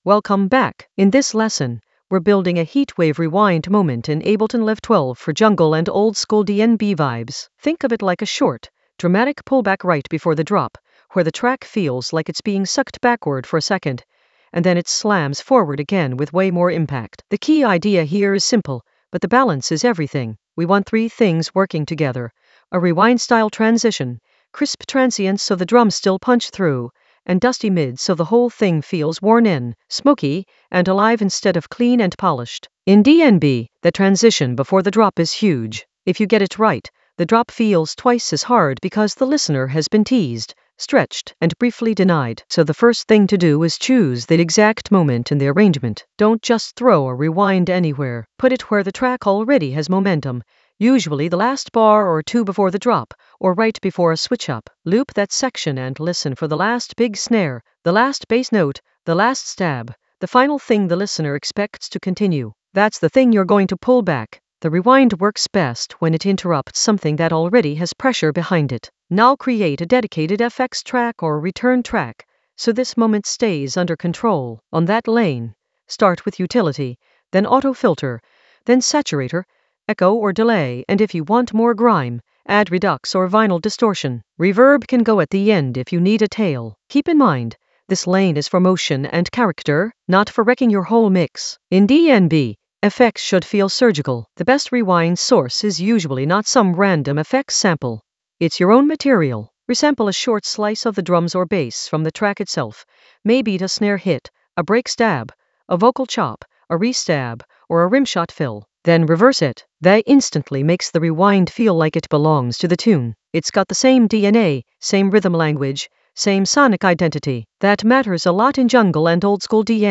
An AI-generated intermediate Ableton lesson focused on Heatwave rewind moment modulate framework with crisp transients and dusty mids in Ableton Live 12 for jungle oldskool DnB vibes in the FX area of drum and bass production.
Narrated lesson audio
The voice track includes the tutorial plus extra teacher commentary.